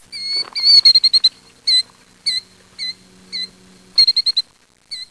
killdeer.wav